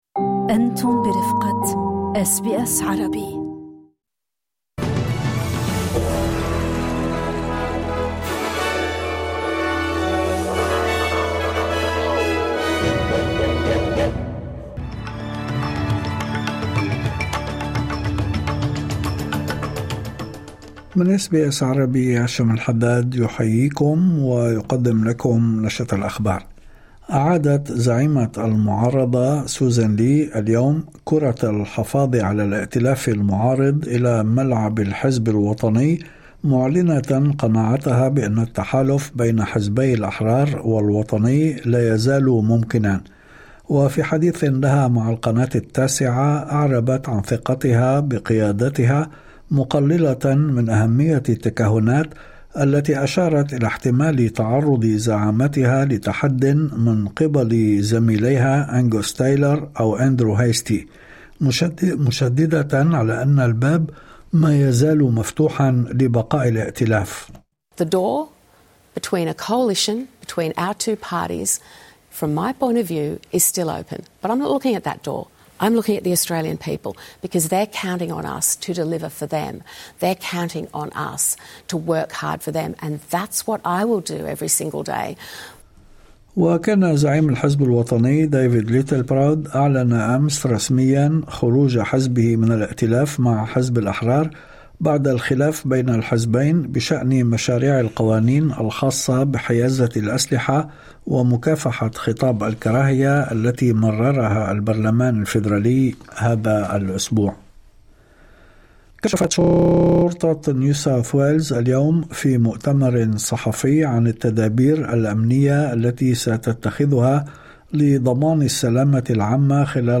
نشرة أخبار الظهيرة 23/1/2026